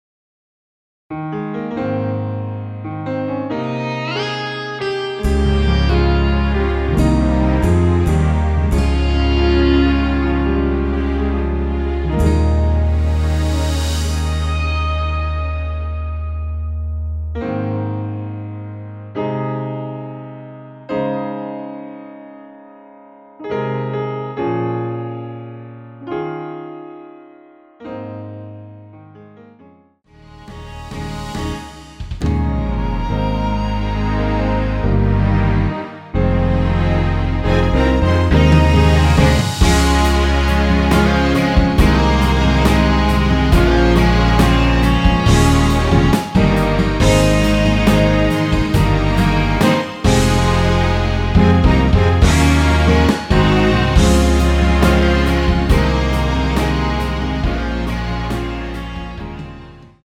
원키에서(-2)내린 MR입니다.
Ab
앞부분30초, 뒷부분30초씩 편집해서 올려 드리고 있습니다.
중간에 음이 끈어지고 다시 나오는 이유는